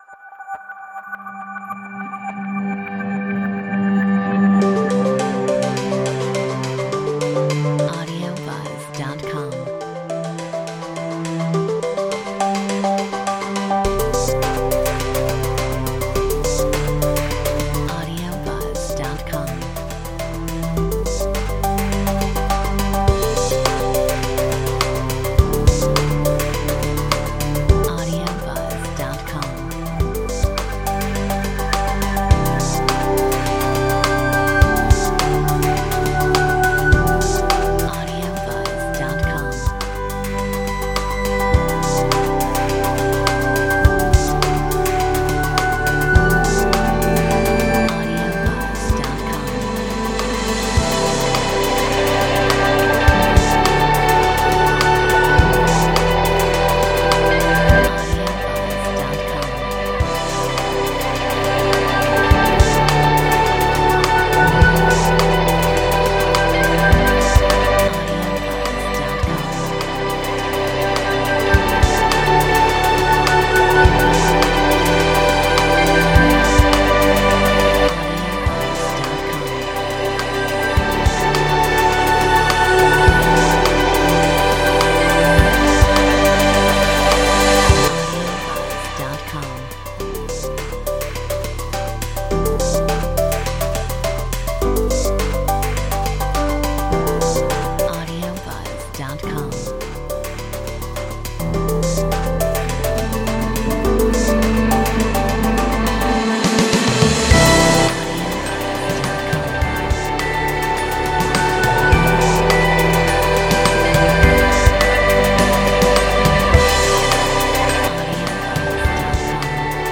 Metronome 104